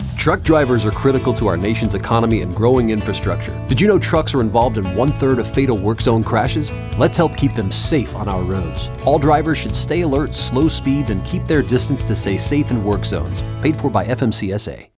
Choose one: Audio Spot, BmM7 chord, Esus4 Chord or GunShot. Audio Spot